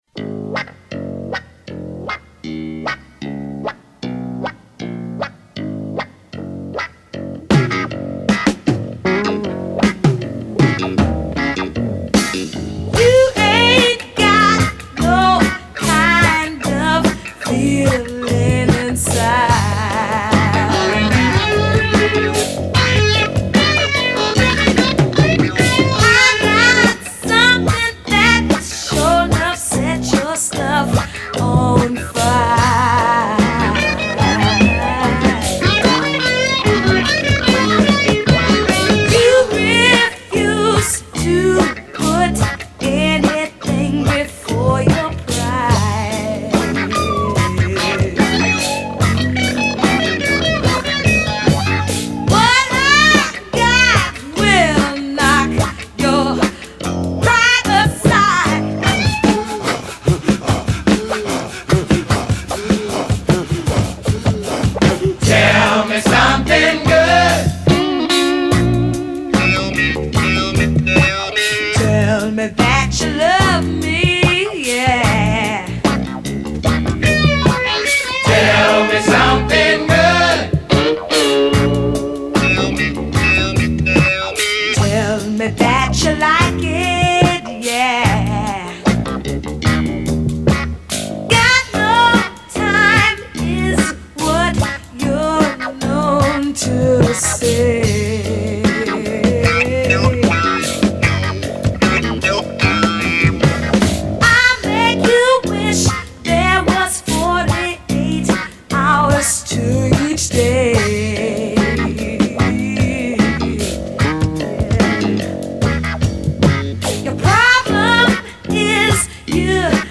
funk